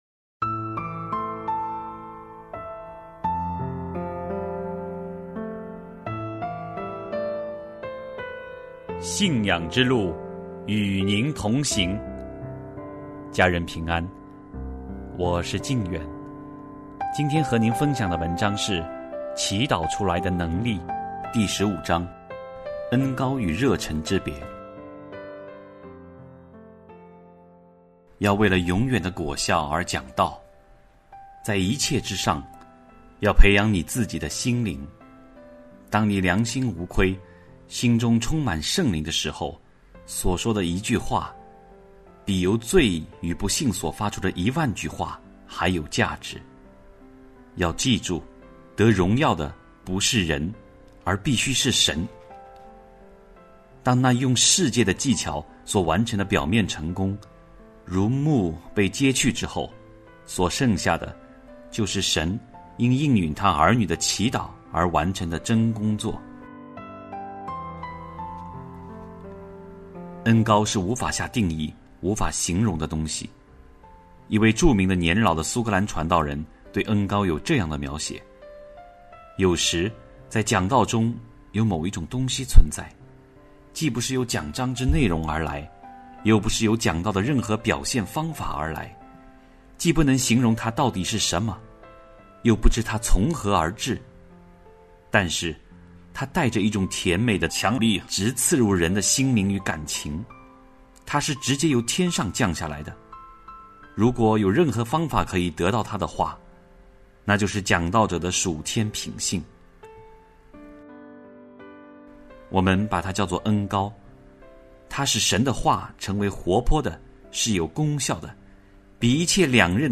首页 > 有声书 | 灵性生活 | 祈祷出来的能力 > 祈祷出来的能力 第十五章：恩膏与热诚之别